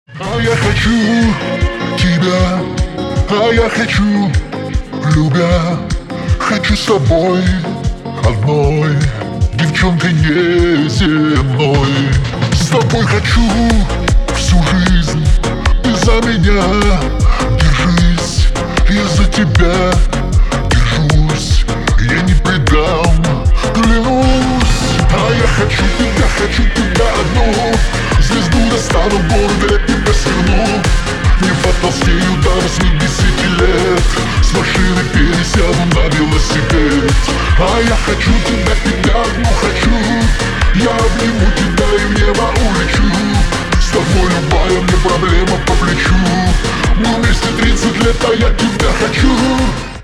• Качество: 320, Stereo
remix
цикличные